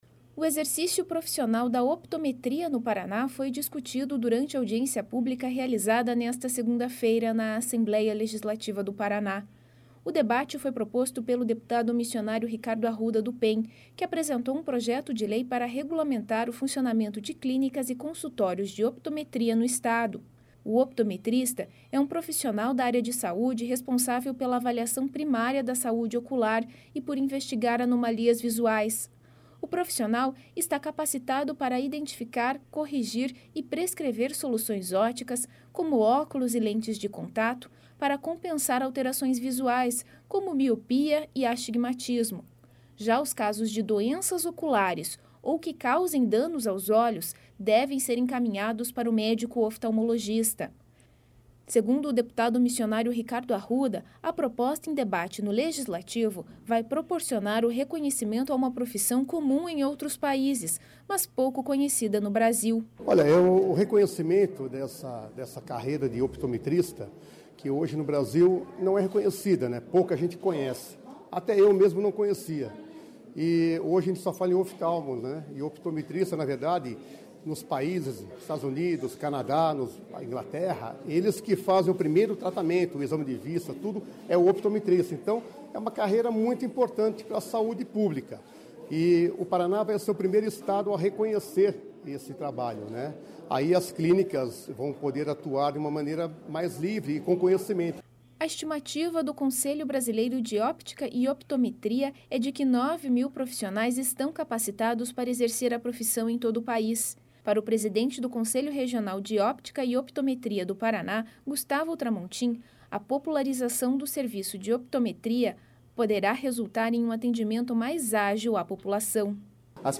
((Descrição do áudio))O exercício profissional da optometria no Paraná foi discutido durante audiência pública realizada nesta segunda-feira (13) na Assembleia Legislativa do Paraná.